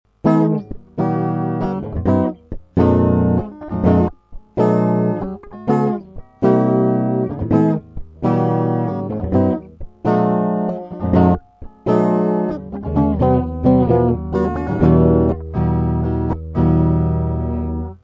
Вступление, он же проигрыш: